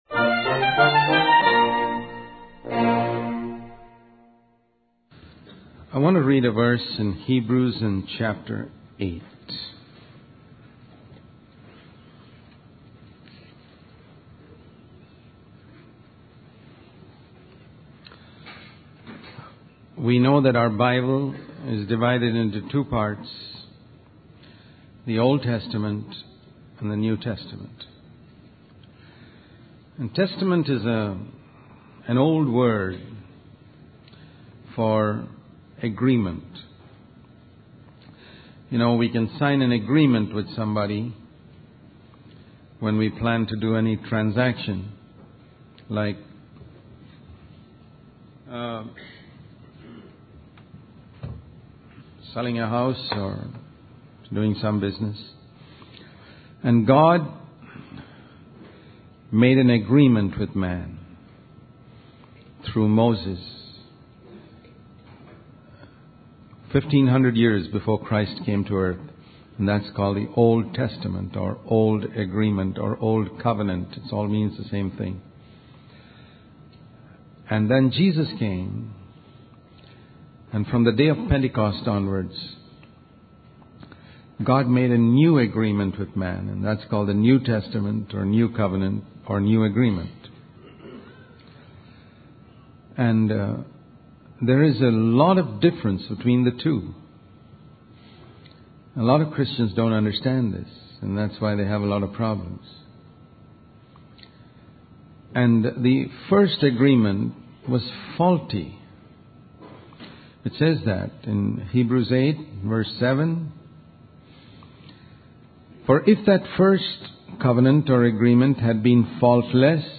In this sermon, the speaker highlights the difference between the Old Testament and the New Testament.